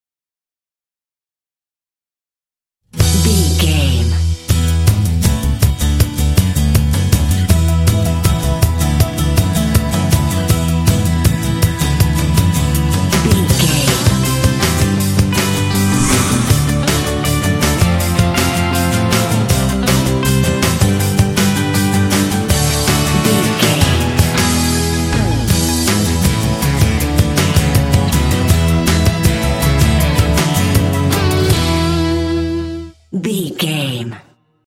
Ionian/Major
Fast
driving
bouncy
happy
groovy
bright
motivational
drums
bass guitar
electric guitar
alternative rock
indie